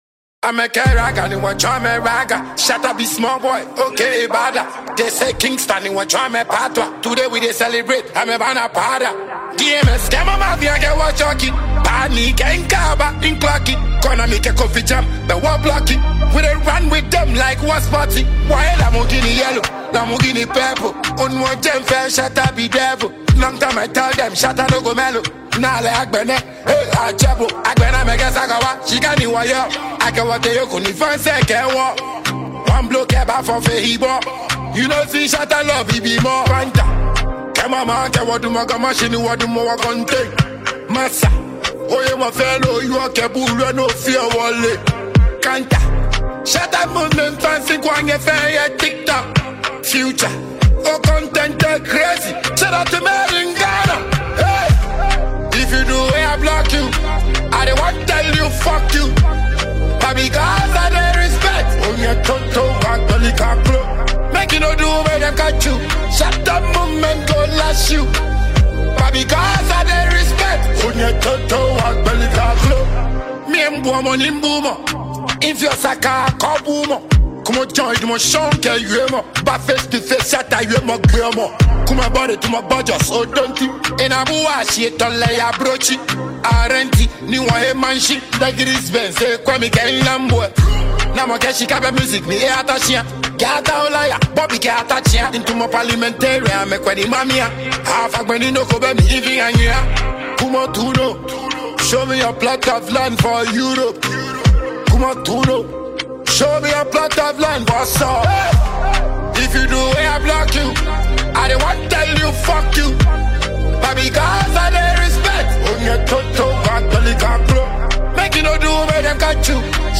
Afrobeats Ghanaian songwriter, singer and dancehall musician